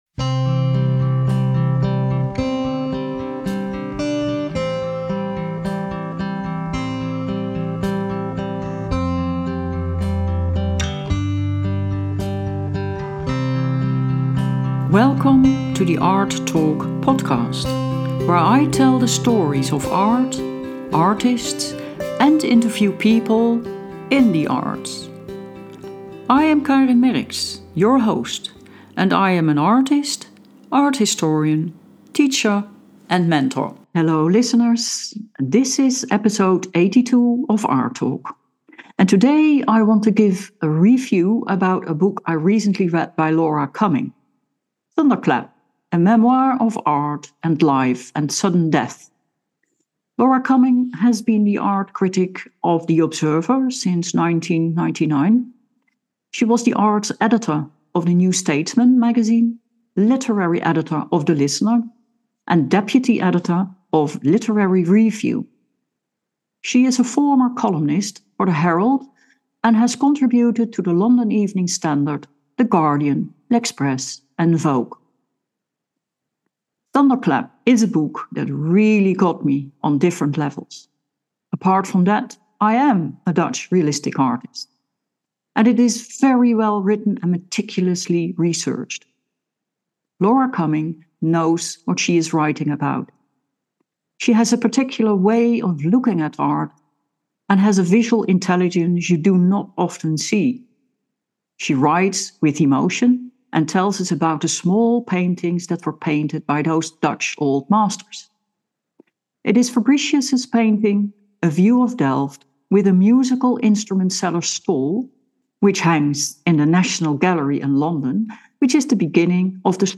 A Book Review of Thunderclap -